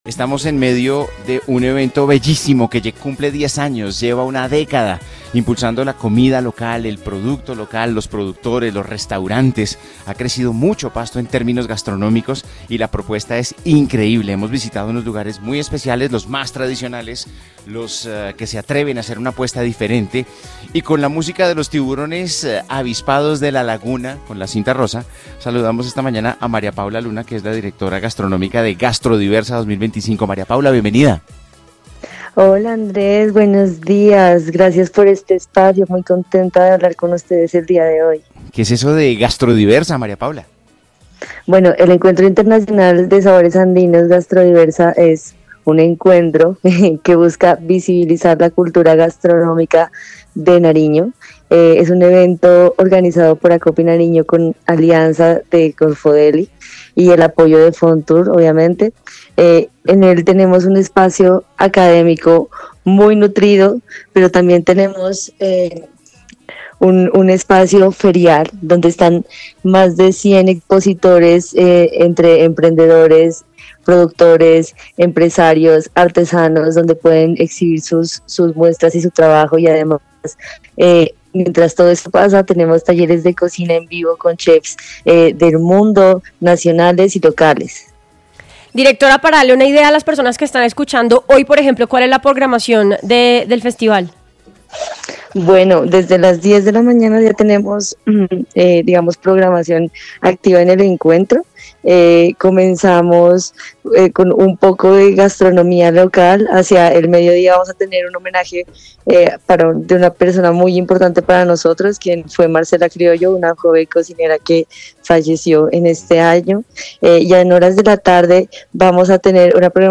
En entrevista con A Vivir Que Son Dos Días